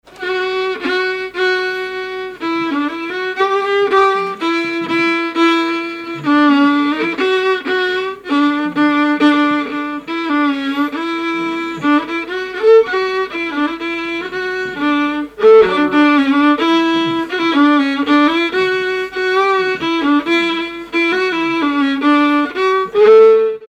Mémoires et Patrimoines vivants - RaddO est une base de données d'archives iconographiques et sonores.
Air
Pièce musicale inédite